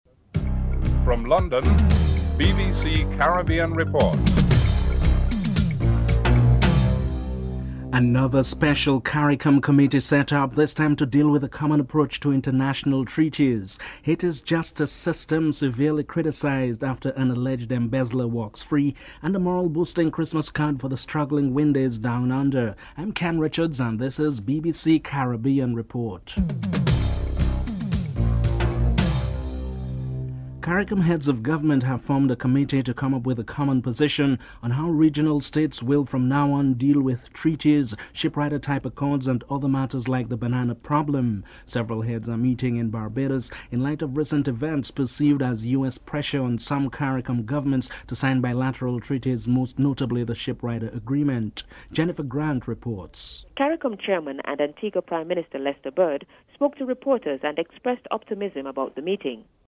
1. Headlines (00:00-00:29)
Prime Ministers P.J. Patterson and Lester Bird are interviewed.